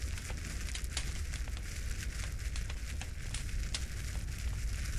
default_furnace.ogg